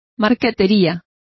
Complete with pronunciation of the translation of marquetry.